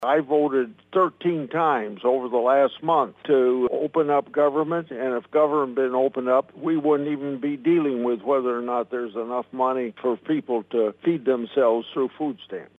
Grassley made his comments this (Monday) afternoon during his weekly Capitol Hill Report with Iowa reporters.